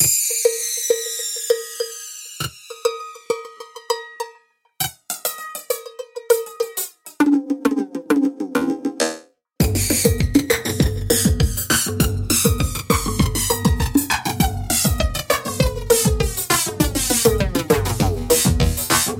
标签： 100 bpm Electronic Loops Drum Loops 3.23 MB wav Key : Unknown
声道立体声